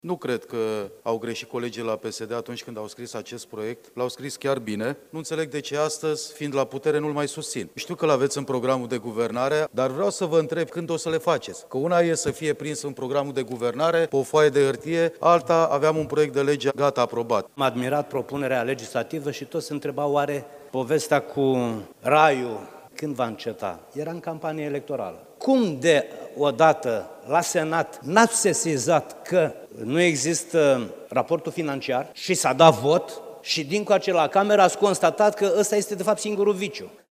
Deputații PNL, Florin Roman și Dumitru Oprea s-au plâns că proiectul respins de colegii de la putere a fost lansat, anul trecut, doar pentru că era campanie electorală.
19apr-16-Roman-si-Oprea-despre-crese.mp3